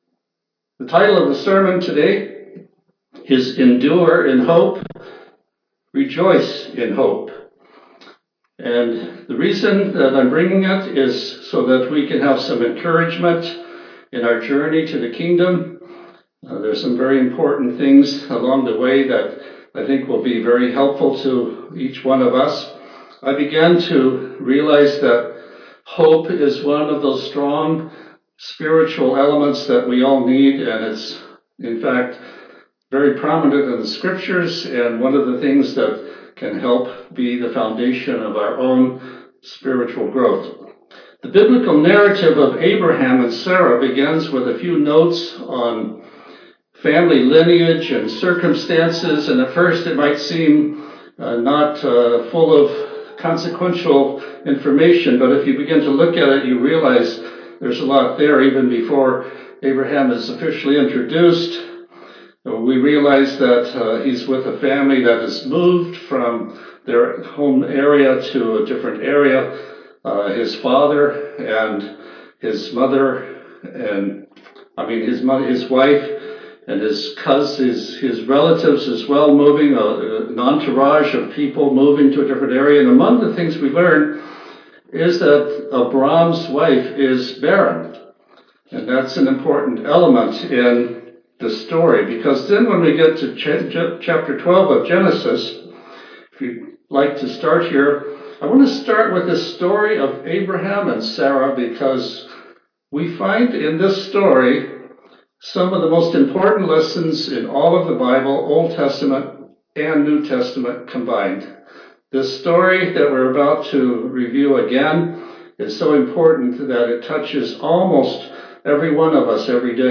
The effect of hope in our life can help us to persevere through the greatest trials of life, growing in character, always enduring in hope, and rejoicing in hope. This sermon shows these encouraging lessons from several examples in the Scriptures.